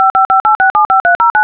Generate a DTMF touch-tone sequence.
dtmf